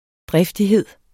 Udtale [ ˈdʁεfdiˌheðˀ ]